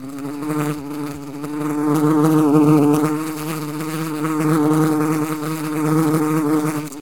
flies2.ogg